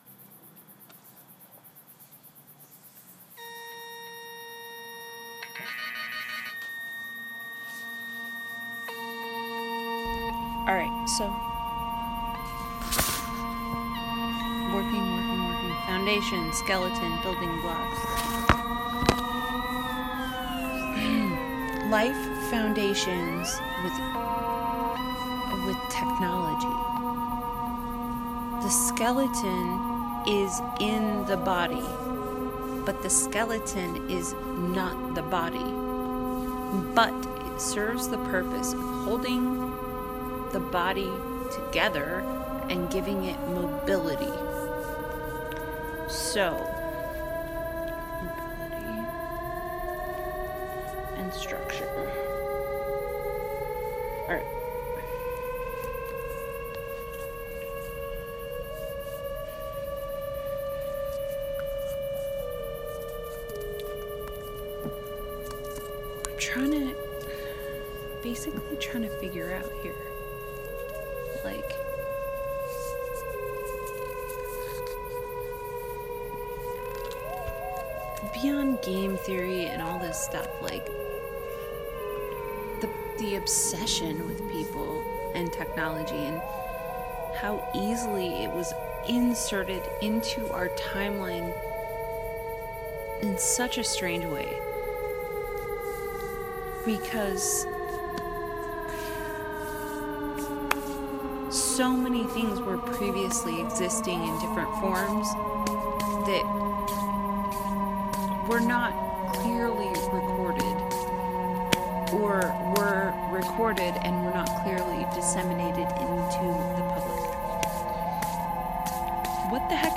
Art, imagination, insight, life, podcast, Sound Exploration
However, the scene is always distorted somehow by the leftover frequencies that pollute the air.
This piece is about a  woman trying to remember the “other world” she uses to live in, and she feels a very strong desire to share these memories with anyone who will listen.  In this world, it is rarely silent, but there are only certain times when it is permissible to speak.  The tones of the reception are measuring the frequency of the output from the people, and if the speaker derails into topics not meant for discussion- the frequency reflects that.
This is a personal compilation of solo creations I have made in the last month.